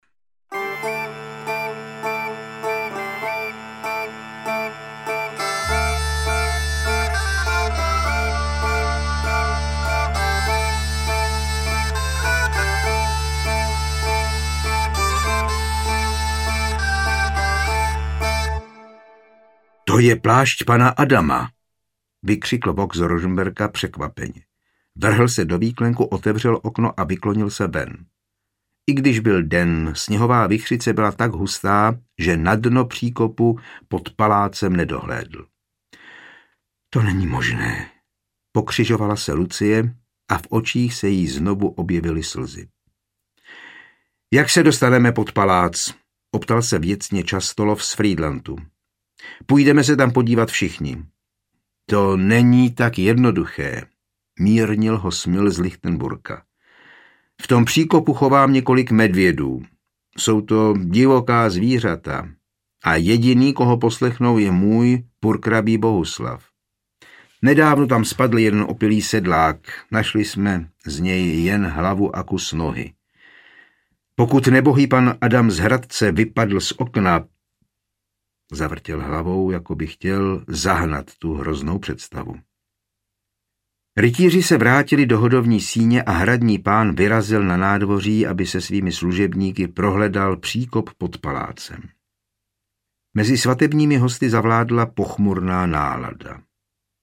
Podivná svatba na Lichnici audiokniha
Ukázka z knihy